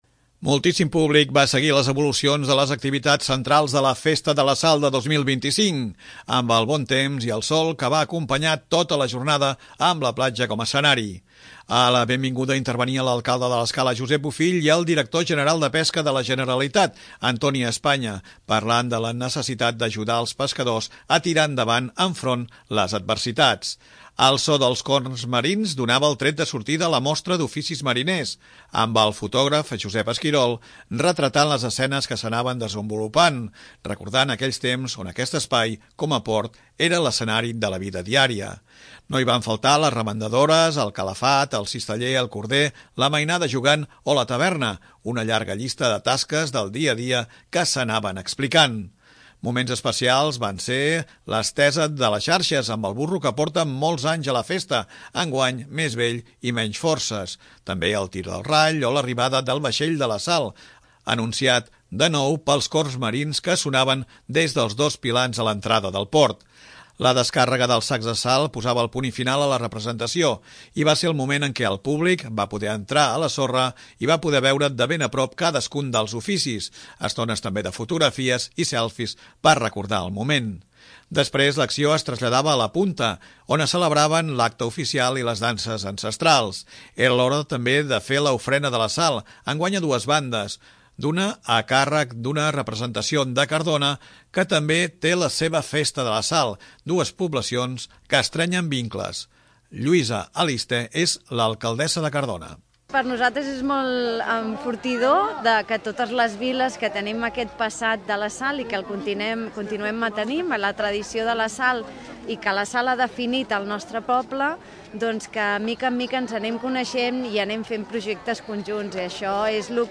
Moltíssim públic va seguir les evolucions de les activitats centrals de la Festa de la Sal de 2025, amb el bon temps i el sol que va acompanyar tota la jornada, amb la platja com escenari.
A la benvinguda intervenien l'alcalde de l'Escala Josep Bofill i el director general de Pesca de la Generalitat, Antoni Espanya, parlant de la necessitat d'ajudar els pescadors a tirar endavant enfront de les adversitats.
També parlava d'aquests intercanvis l'alcalde Josep Bofill.